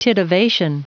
Prononciation du mot titivation en anglais (fichier audio)
Prononciation du mot : titivation